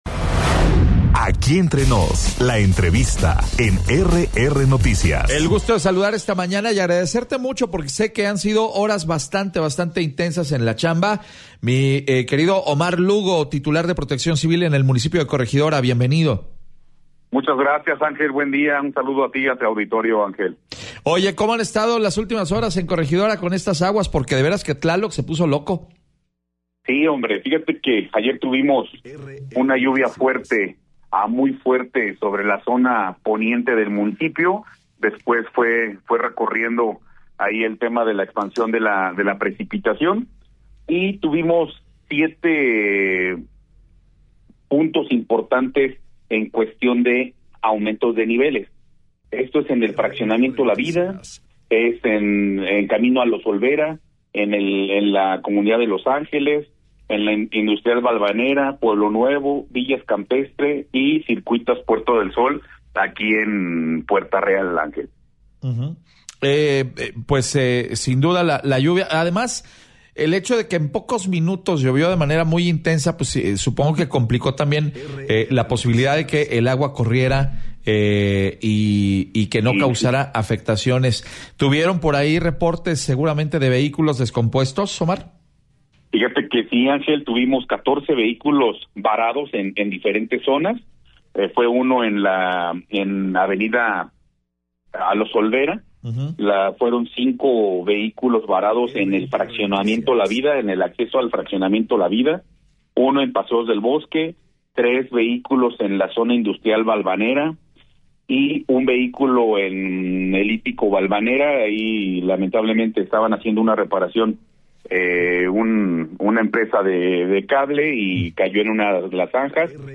EntrevistasMunicipiosPodcastU2